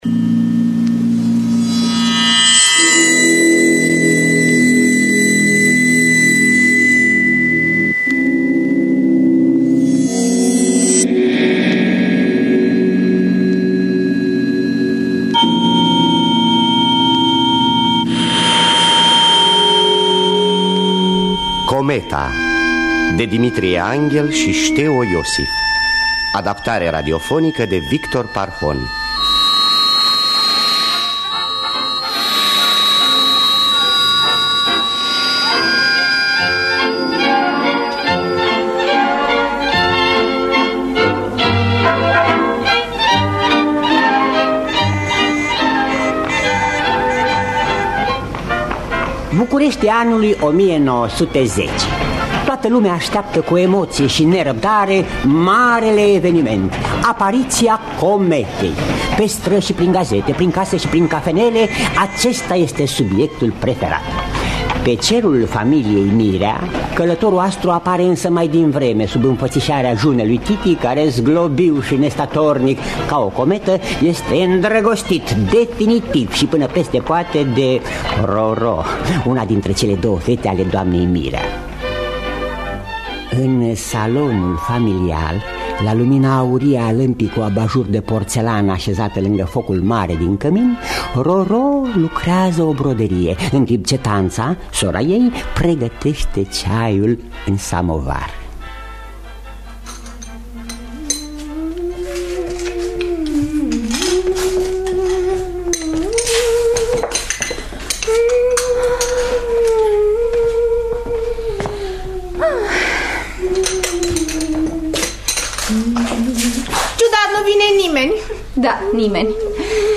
Adaptarea radiofonică de Victor Parhon.